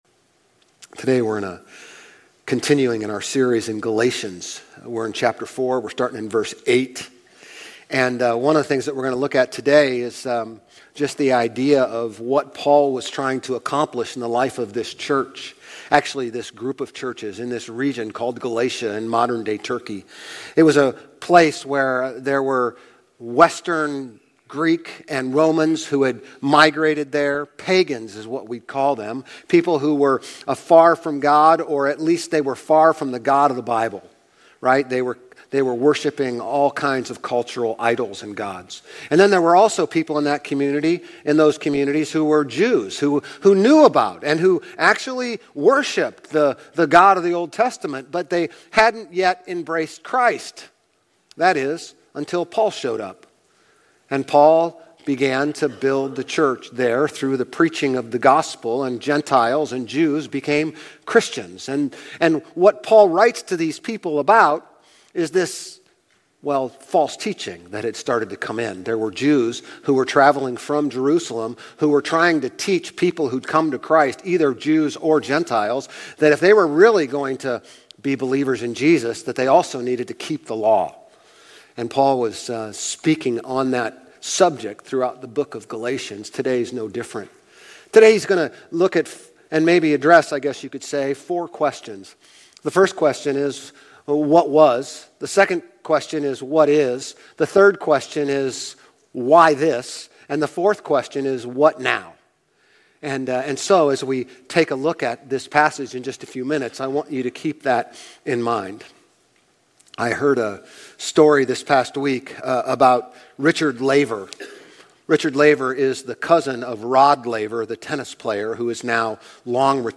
Grace Community Church Old Jacksonville Campus Sermons Galatians 4:8-31 May 20 2024 | 00:30:15 1x 00:00 / 00:30:15 Subscribe Share RSS Feed Share Link Embed